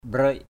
/ɓrəɪʔ/